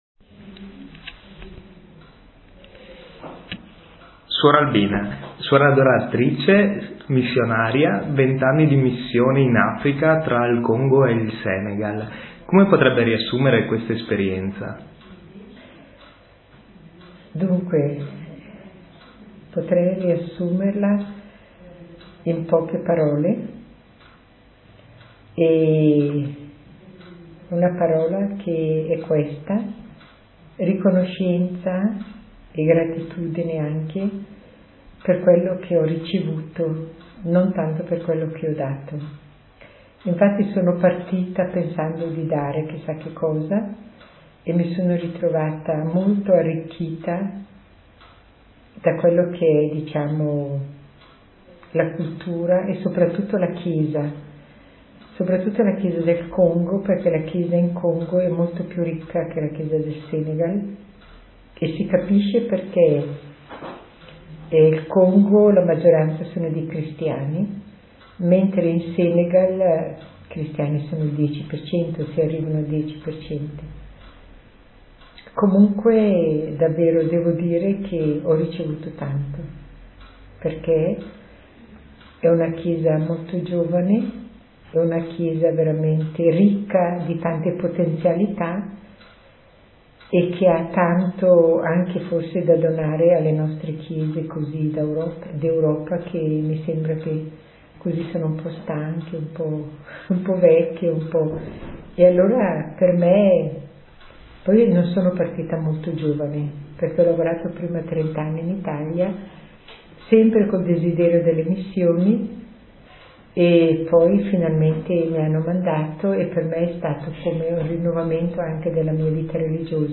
Intervista audio